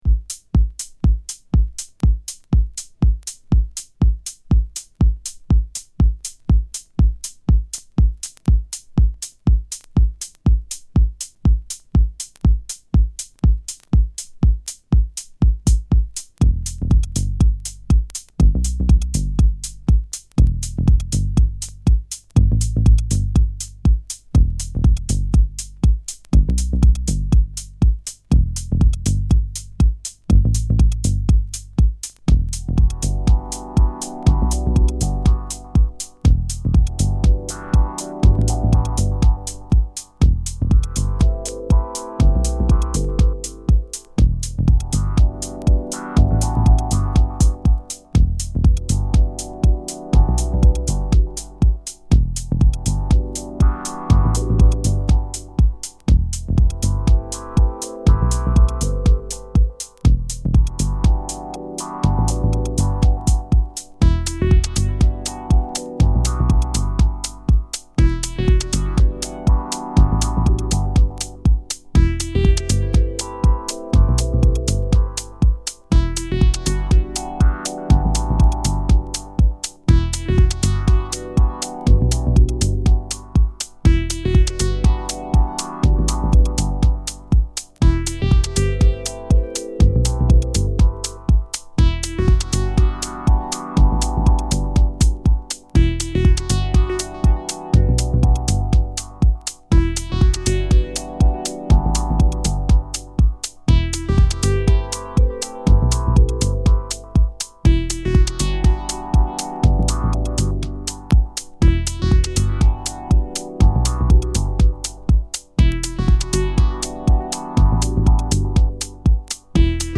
Acid , Deep House
Techno